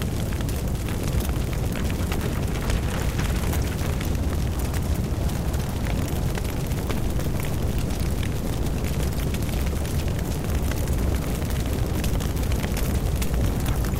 11_pozhar v pomechenyy.ogg